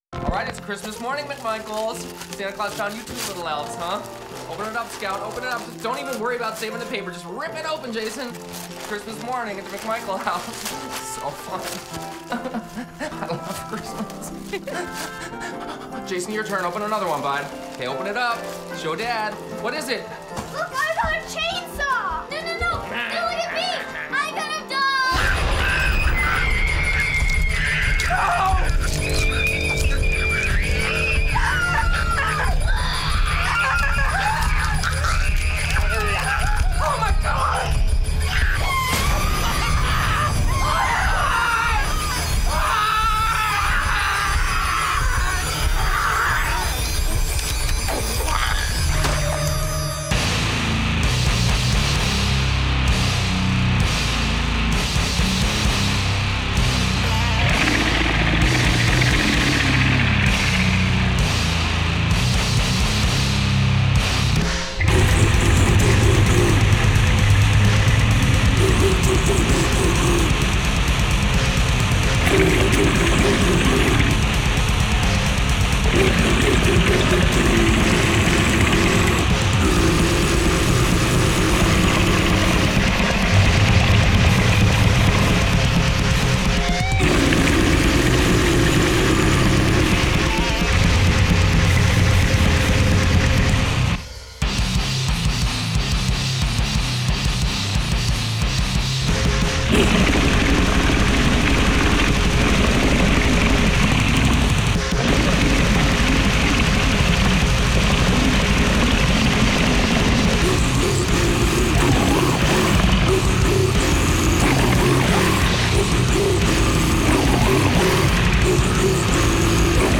pure brutal death sounds suitable for christmas